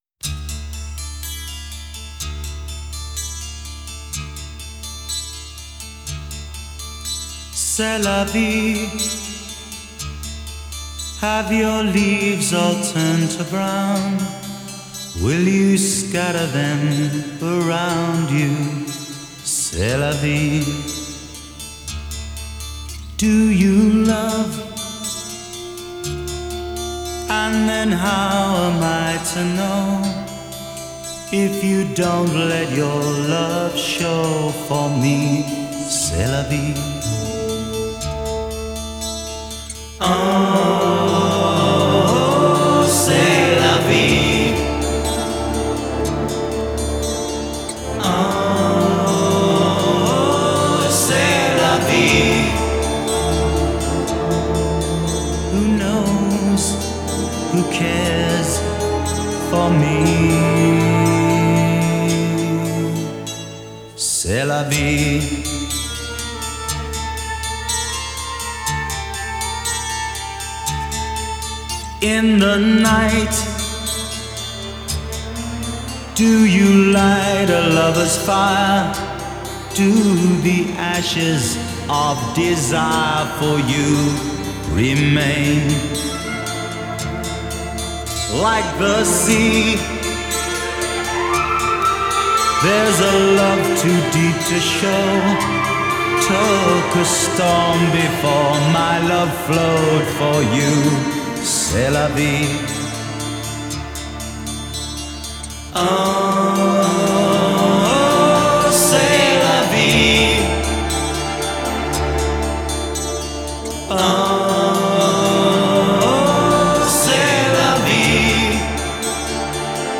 rock music progressive rock